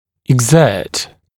[ɪg’zɜːt][иг’зё:т]прилагать (о силе, усилиях)